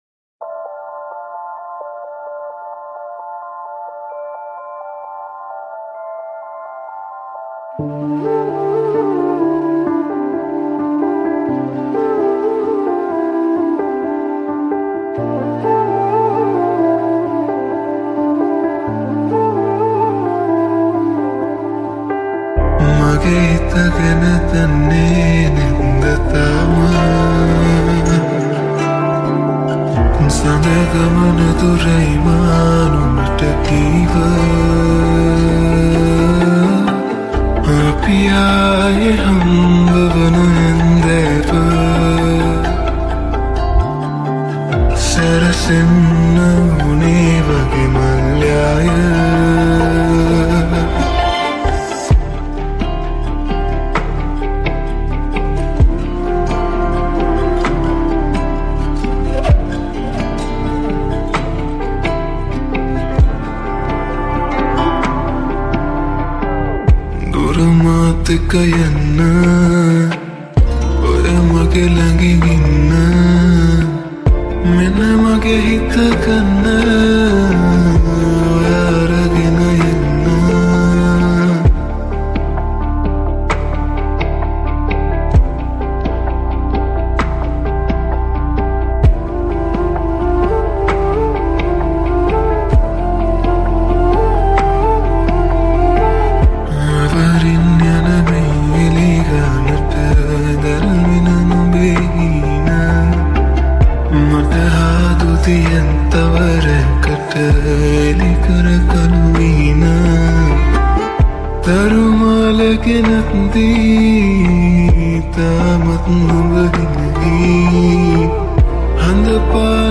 slowed+reverb